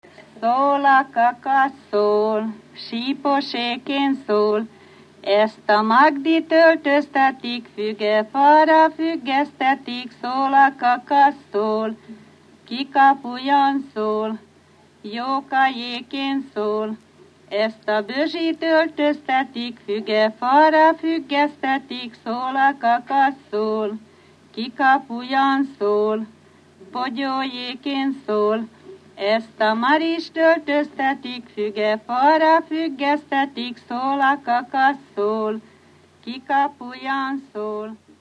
Felföld - Bars vm. - Aha
Műfaj: Párosító
Stílus: 7. Régies kisambitusú dallamok